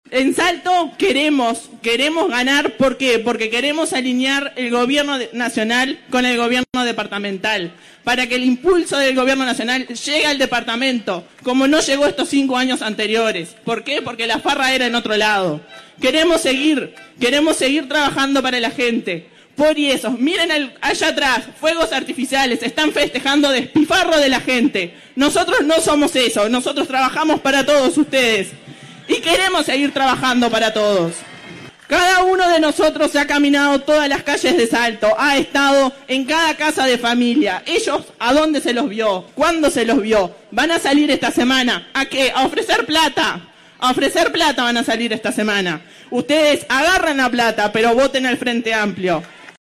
Multitudinario acto en la Plaza Estigarribia